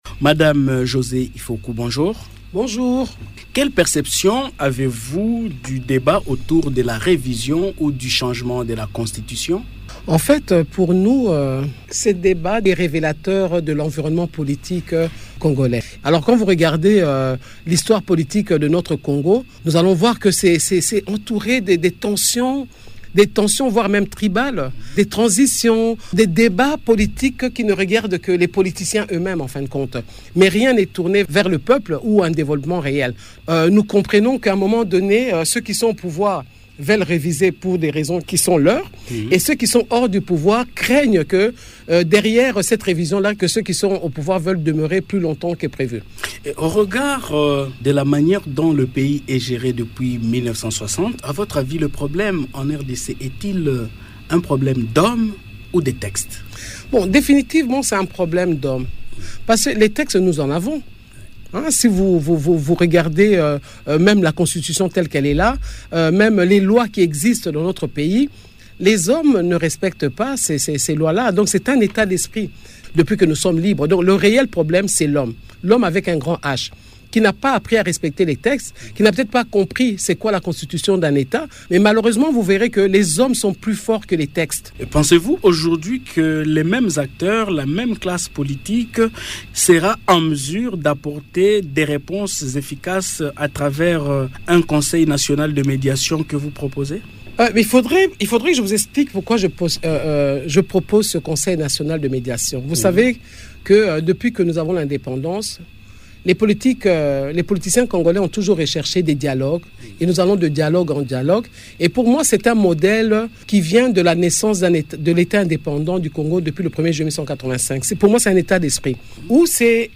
Au cours d’une interview ce mardi 26 novembre à Radio Okapi, Marie-Josée Ifoku, candidate malheureuse à la présidentielle de 2018 et 2023 et ancienne gouverneur de province de la Tshuapa, plaide pour la création d'un Conseil national de médiation pour renforcer la cohésion nationale.